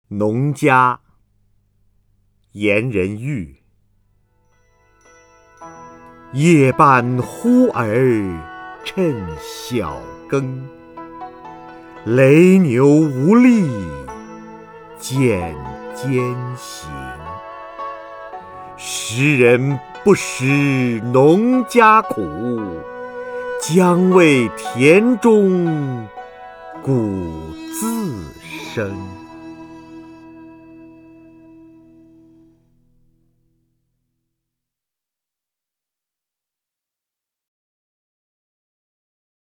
瞿弦和朗诵：《农家》(（唐）颜仁郁) （唐）颜仁郁 名家朗诵欣赏瞿弦和 语文PLUS